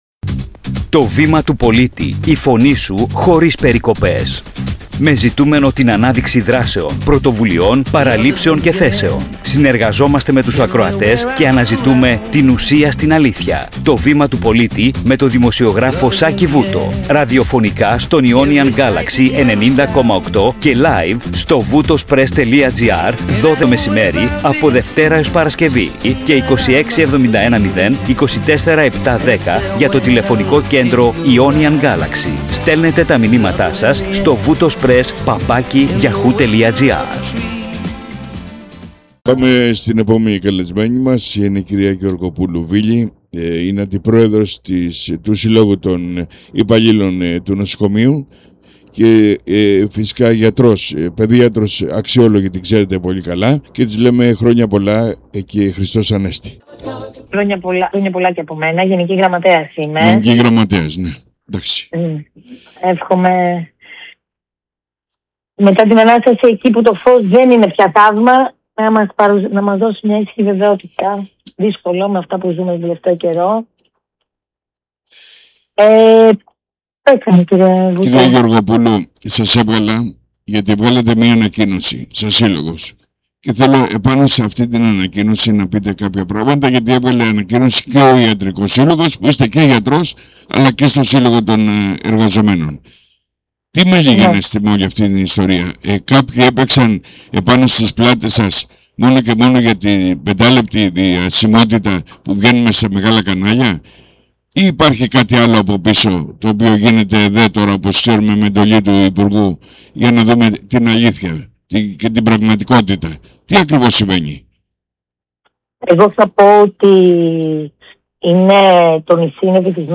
Η συζήτηση γίνεται σε ραδιοφωνική εκπομπή και έχει δύο βασικούς άξονες: